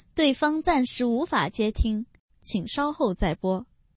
avchat_peer_reject.mp3